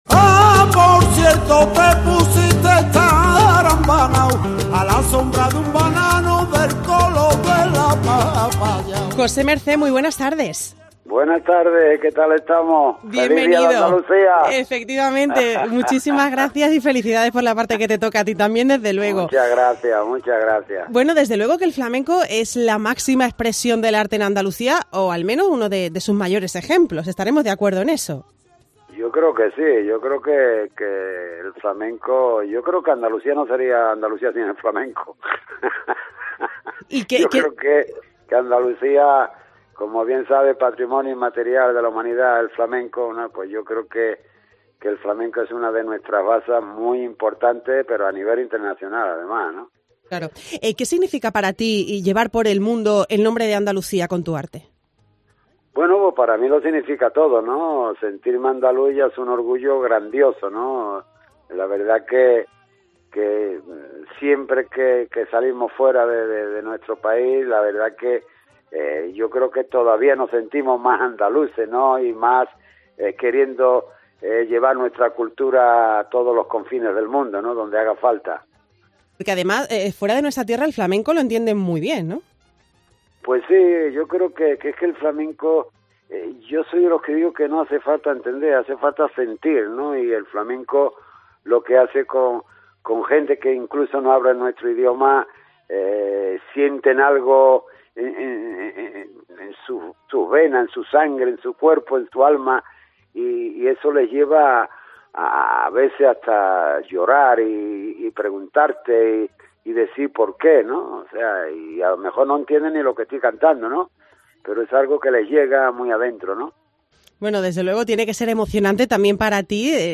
En este 28 de febrero, Día de Andalucía, no hemos querido pasar por alto la celebración en 'Andalucía Va de Cultura' y por eso hemos querido invitar a compartir con el programa esta jornada tan especial a es uno de los artistas flamencos más importantes que ha dado Andalucía en las últimas décadas.